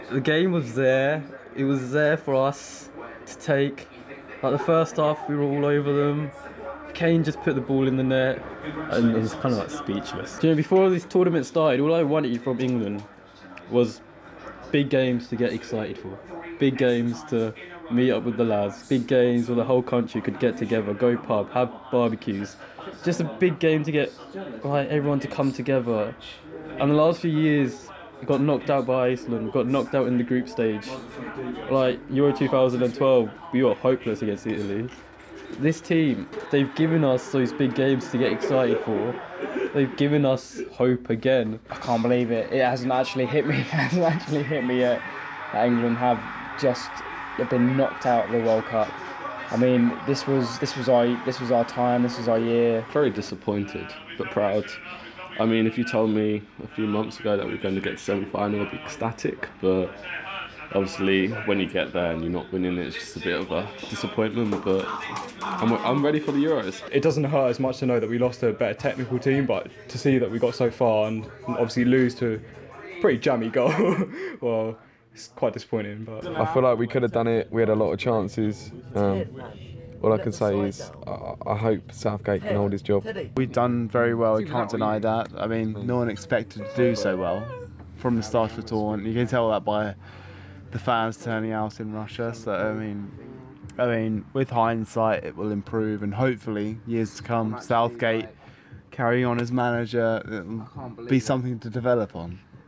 Listen: England fans in Medway have been reacting to last night's defeat in the semi-final of the World Cup - 12/07/2018